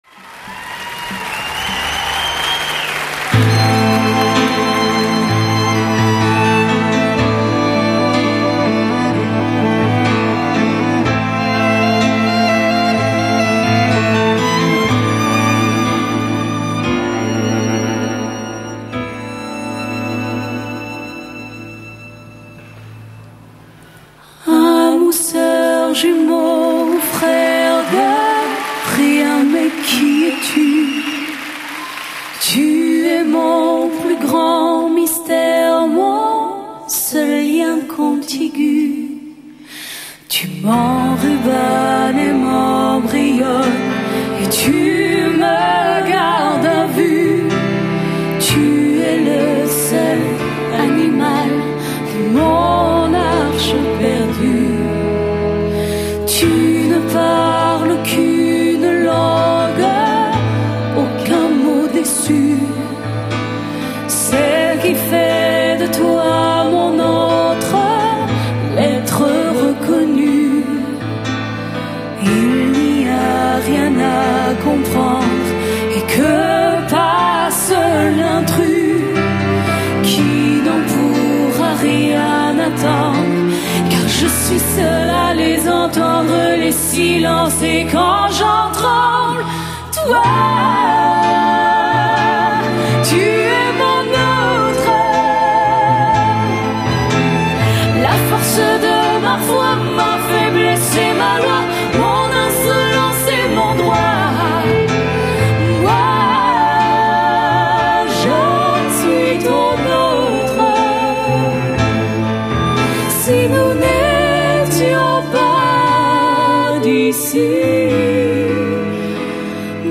以其特有的粗犷而富有激情的磁性嗓音